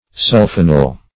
Search Result for " sulphonal" : The Collaborative International Dictionary of English v.0.48: Sulphonal \Sul"pho*nal\, n. (Med.)